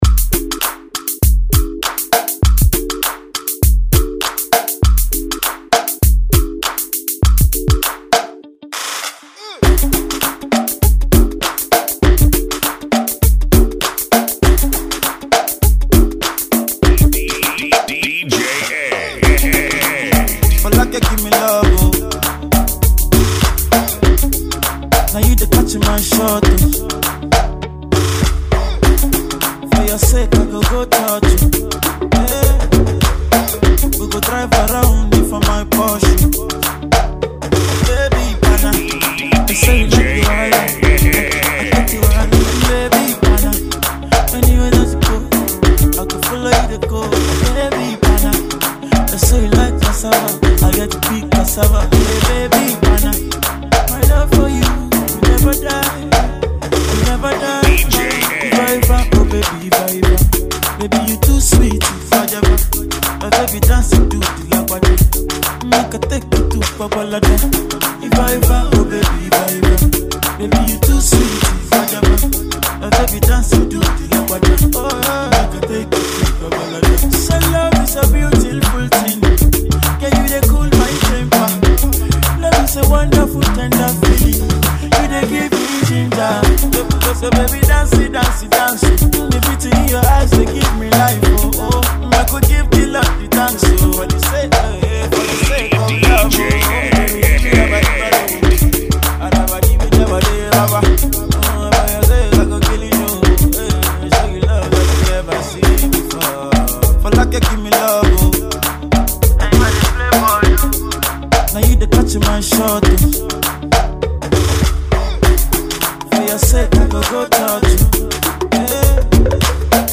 Funky [ Bpm 100